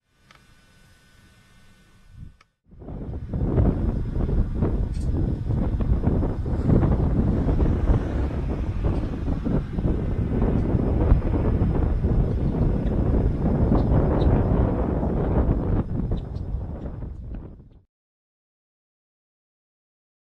Yellow-headed Blackbird  MOV  MP4  M4ViPOD  WMV